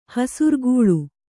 ♪ hasurgūḷu